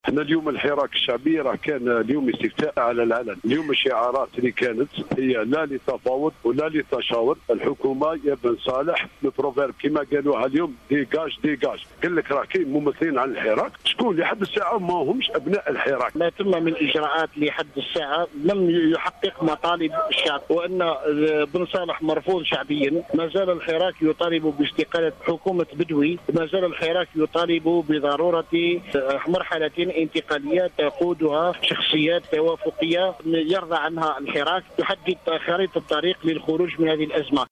عينة / مراسلة من إذاعة الجزائر من سطيف